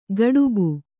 ♪ gaḍubu